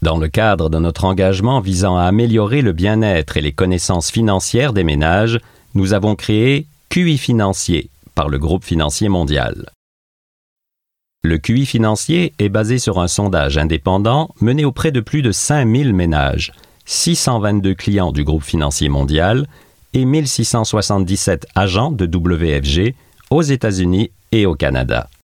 Voice Samples: Financier Formation
male